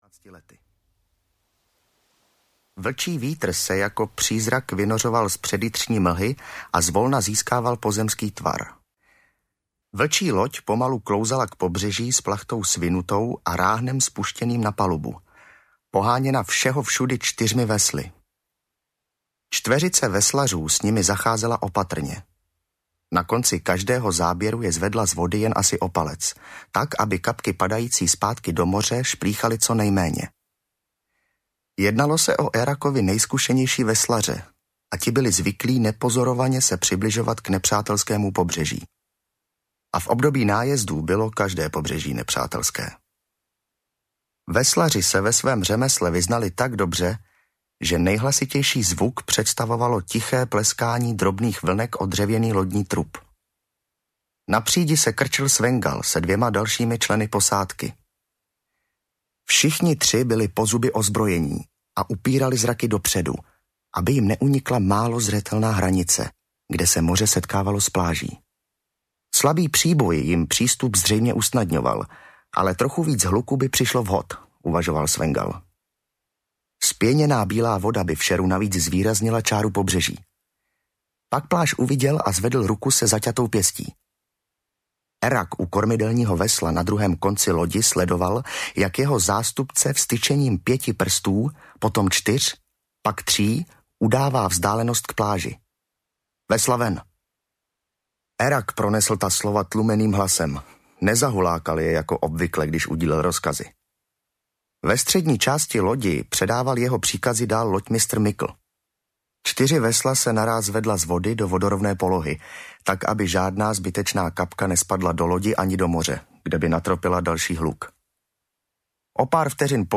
Audio knihaBratrstvo Kniha první - Vyděděnci
Ukázka z knihy
• InterpretPavel Neškudla